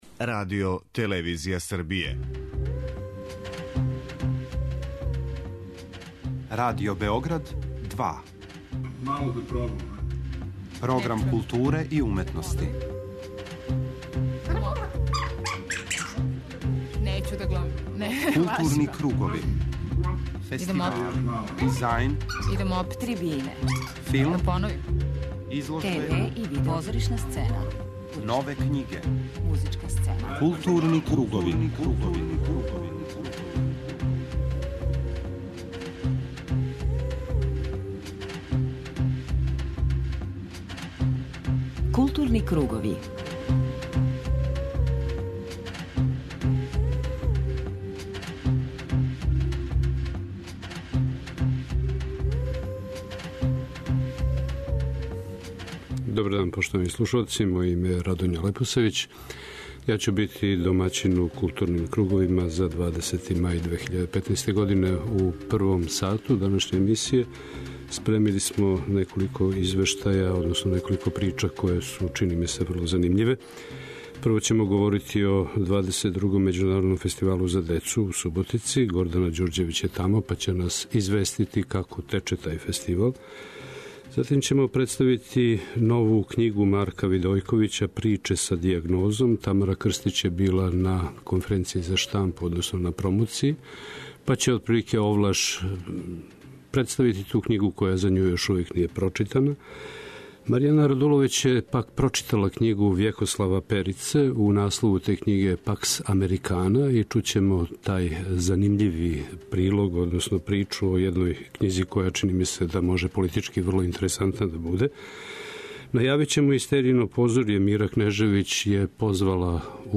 преузми : 53.69 MB Културни кругови Autor: Група аутора Централна културно-уметничка емисија Радио Београда 2.